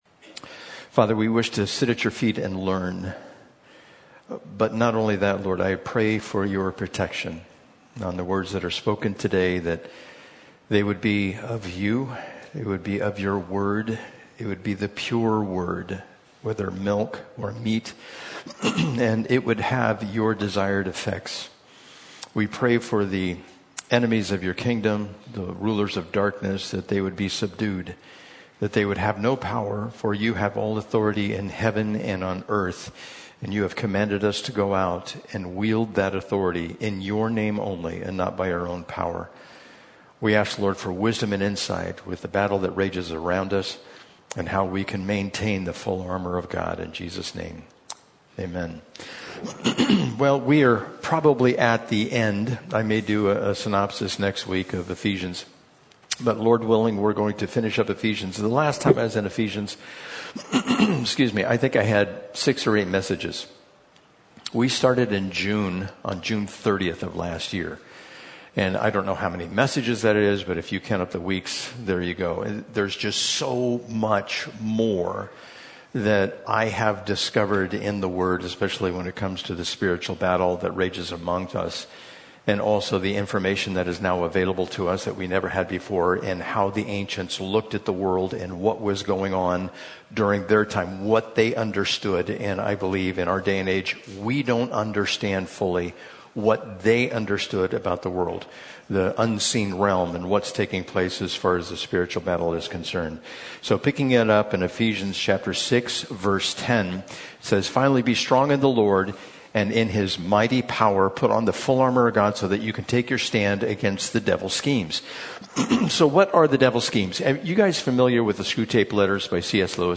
Audio Teachings of Calvary Chapel Lakeside